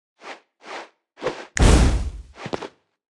Media:Sfx_Anim_Ultra_Wizard.wav 动作音效 anim 在广场点击初级、经典、高手和顶尖形态或者查看其技能时触发动作的音效
Sfx_Anim_Ultra_Wizard.wav